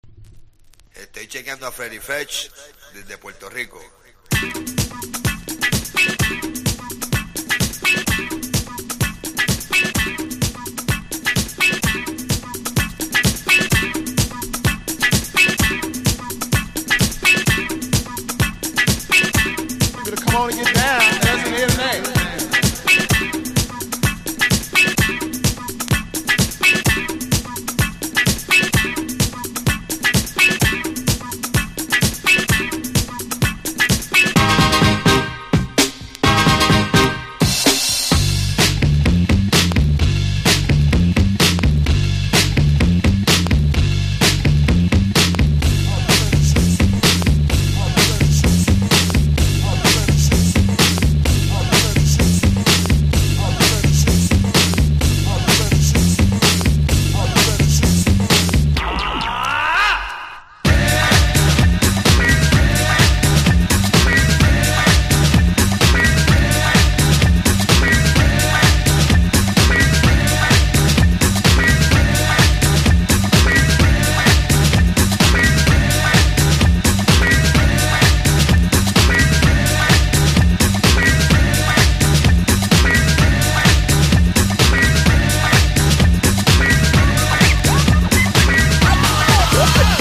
BREAK BEATS / BIG BEAT